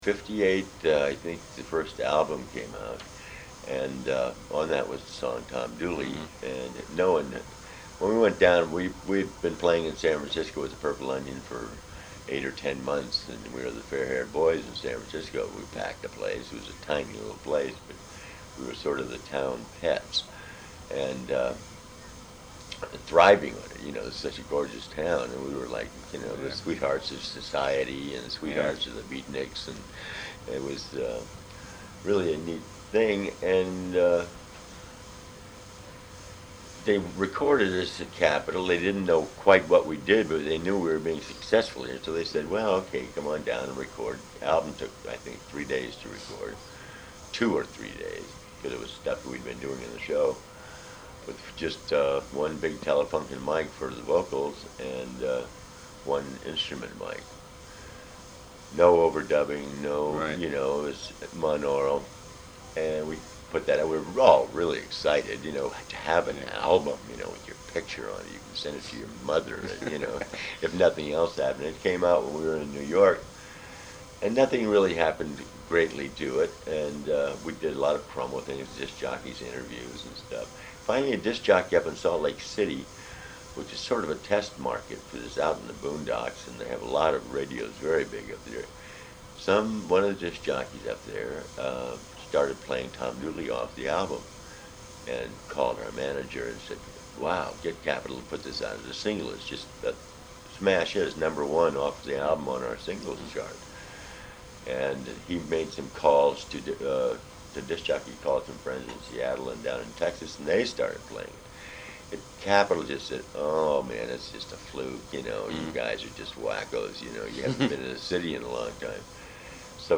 Here are some interviews I did when I was writing for GI (along with a few sound-bites)…